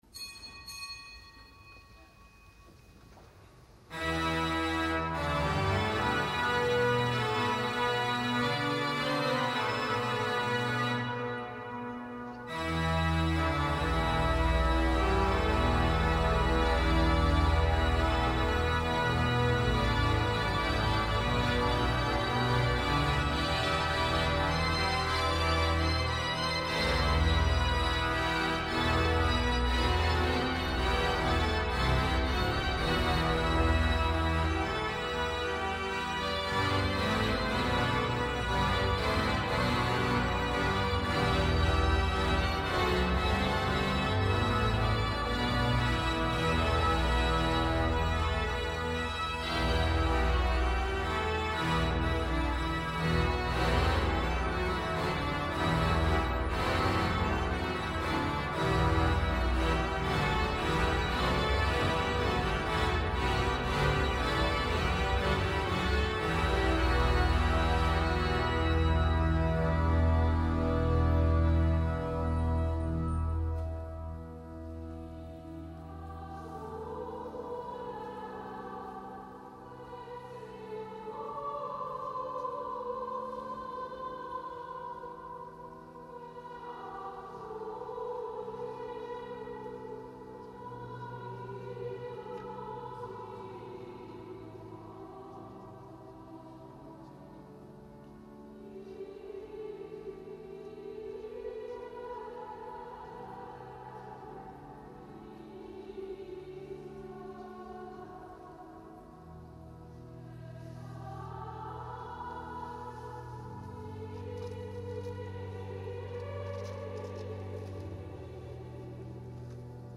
Kapitelsamt aus dem Kölner Dom am zweiten Sonntag im Jahreskreis.